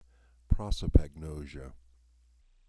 This plays the word pronounced out loud.